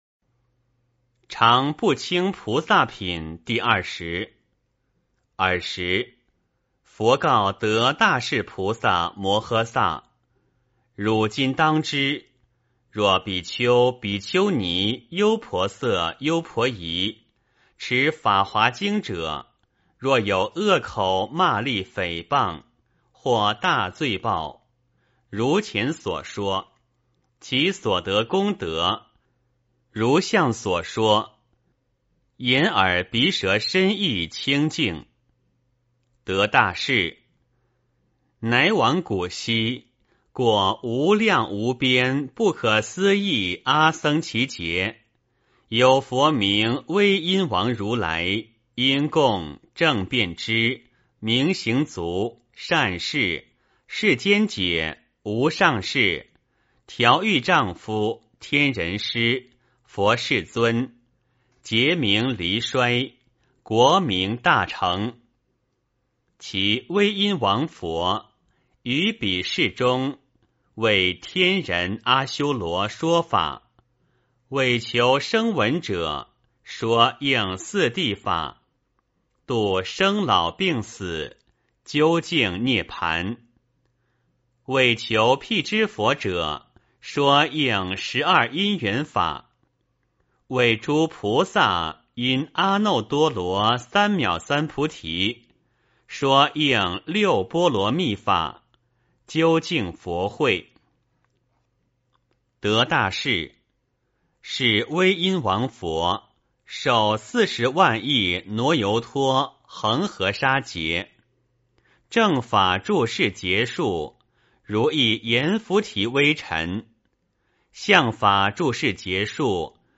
法华经-常不轻菩萨品第二十 诵经 法华经-常不轻菩萨品第二十--未知 点我： 标签: 佛音 诵经 佛教音乐 返回列表 上一篇： 法华经-法师功德品第十九 下一篇： 法华经-妙音菩萨品第二十四 相关文章 世尊释迦牟尼佛赞--莫尔根 世尊释迦牟尼佛赞--莫尔根...